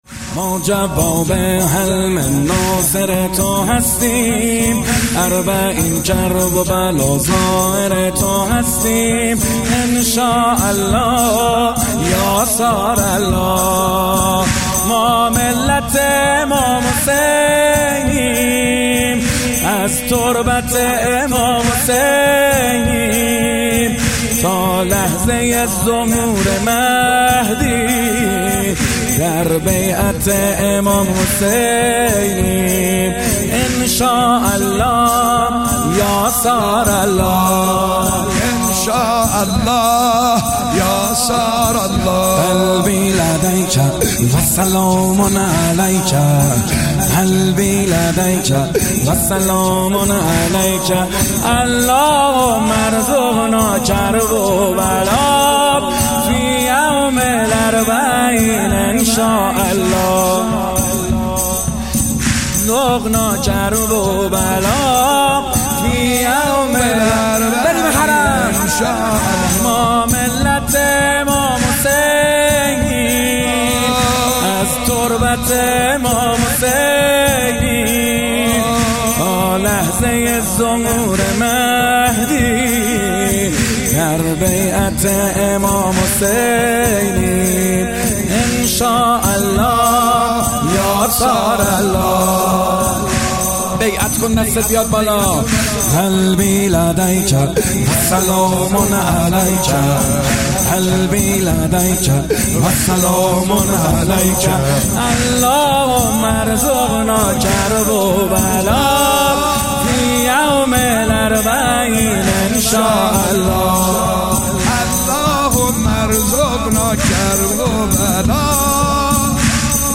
مداحی کربلایی محمد حسین حدادیان | محرم الحرام 1399 هیئت رزمندگان اسلام